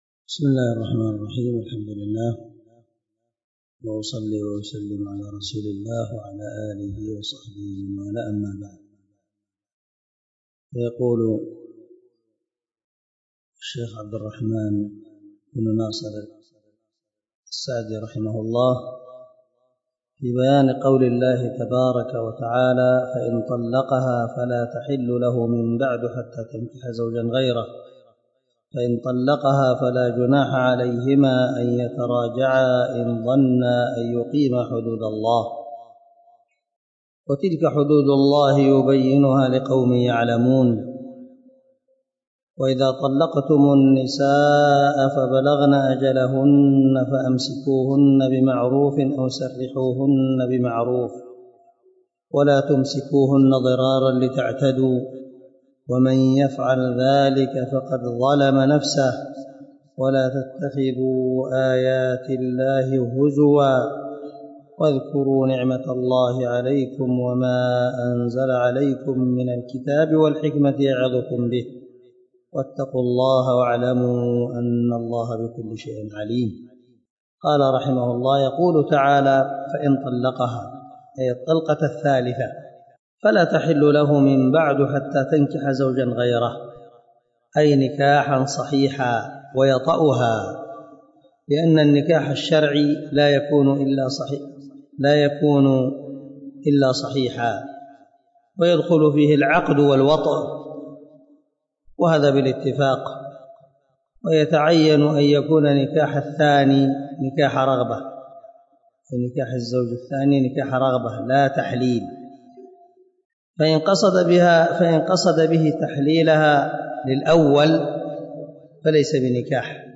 118الدرس 108 تابع تفسير آية ( 230 – 231 ) من سورة البقرة من تفسير القران الكريم مع قراءة لتفسير السعدي
دار الحديث- المَحاوِلة- الصبيحة.